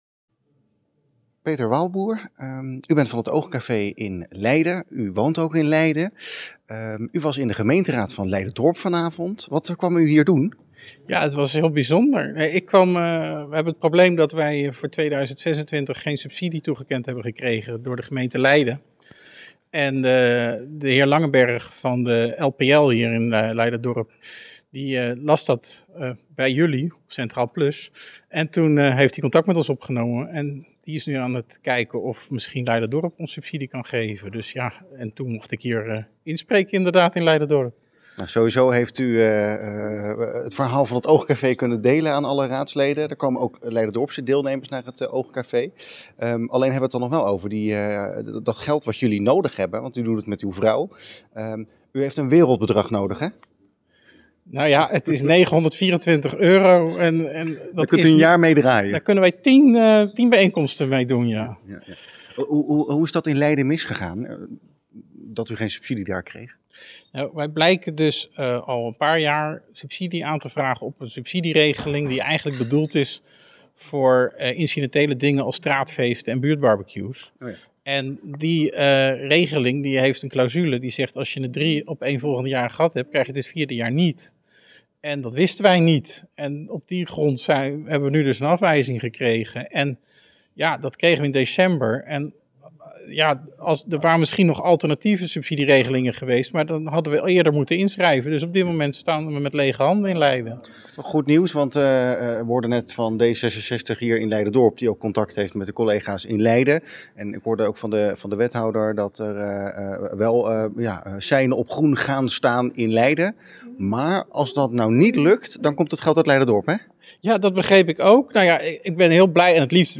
na afloop van de vergadering